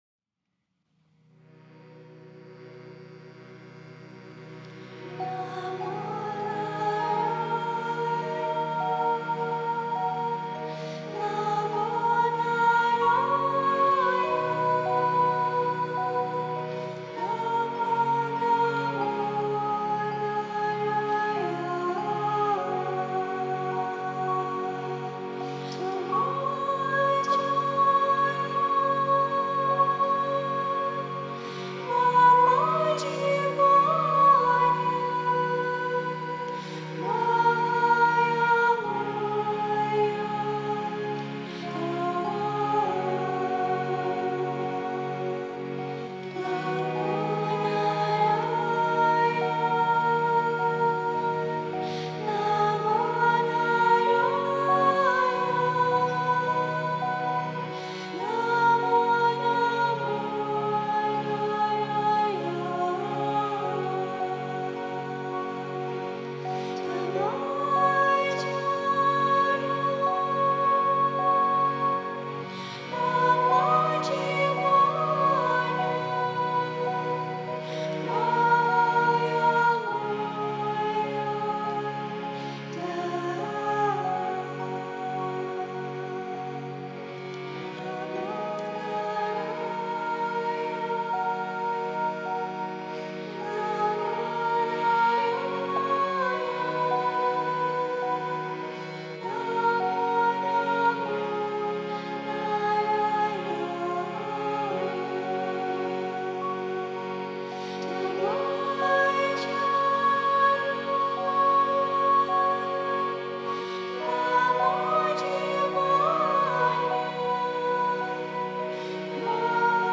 instrumental and vocal ensemble
soulful arrangements
featuring harmonium, glockenspiel, guitar and percussion.